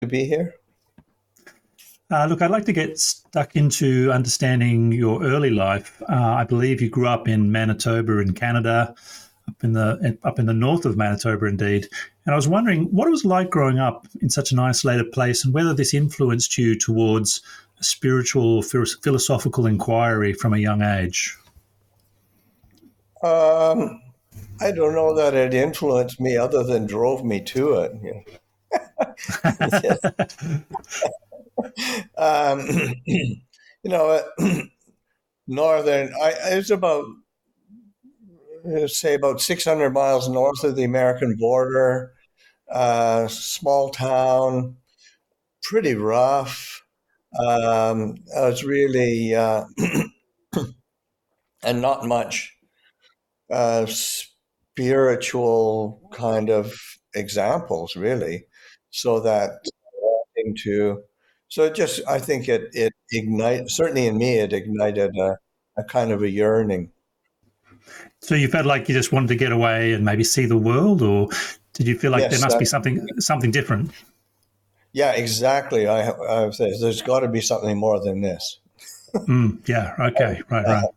Online interview